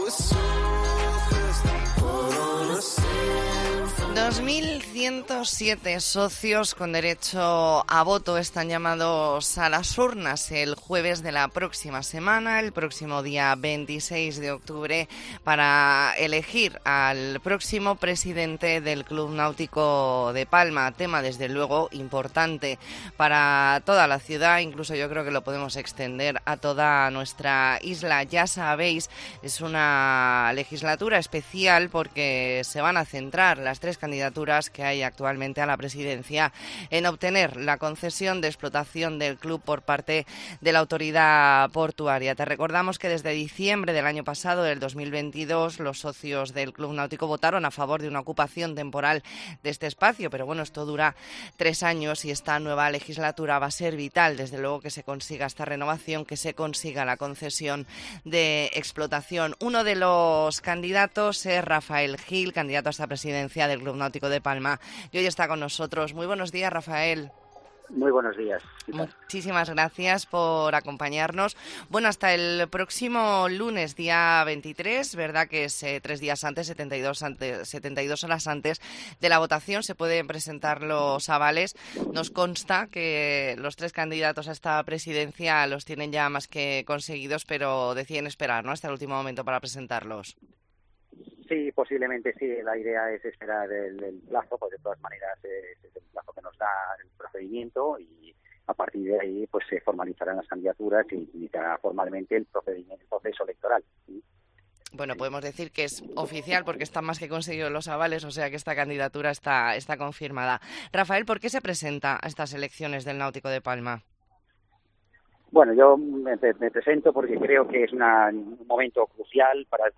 Entrevista en La Mañana en COPE Más Mallorca, jueves 19 de octubre de 2023.